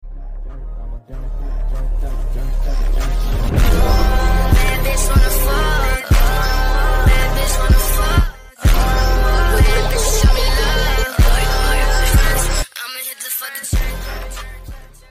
The quality is a bit bad..